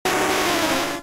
Cri de Nidoqueen K.O. dans Pokémon Diamant et Perle.